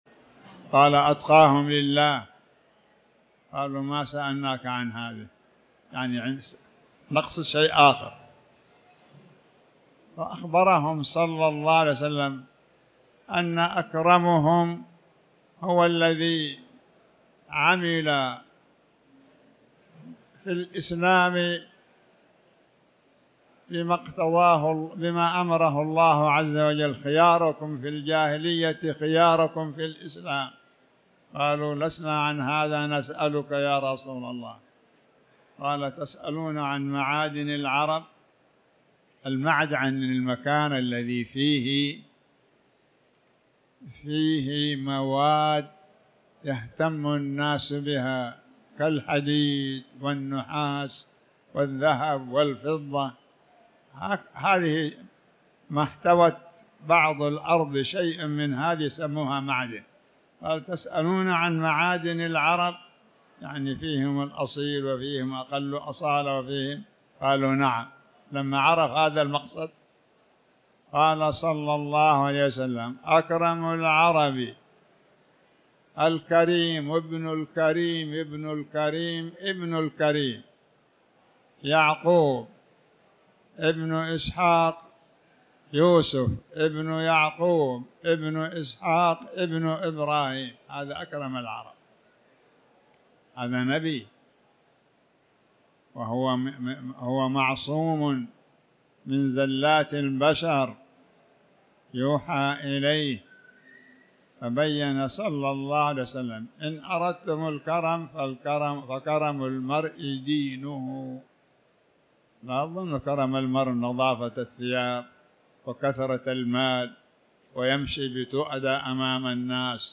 تاريخ النشر ١٣ شوال ١٤٤٠ هـ المكان: المسجد الحرام الشيخ